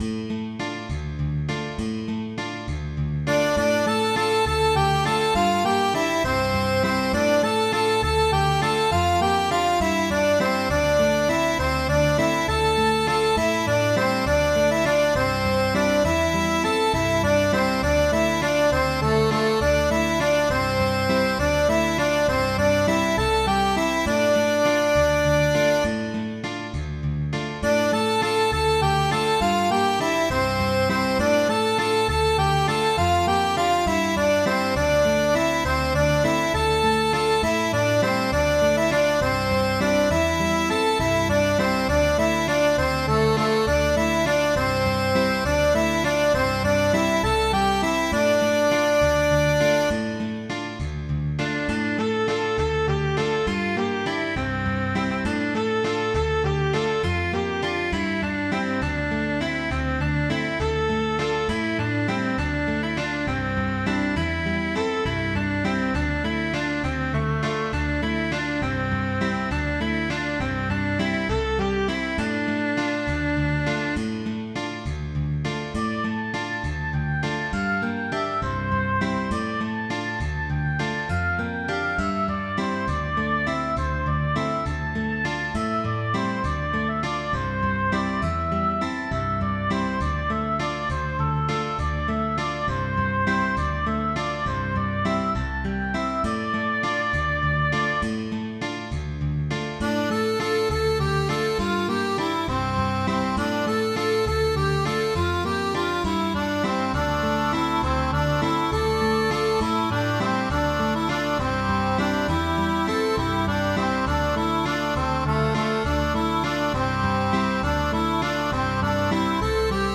Midi File, Lyrics and Information to The Fish Of The Sea
This was a capstan shanty, and sailors would take turns with verses, giving a new fish each time for as long as was necessary.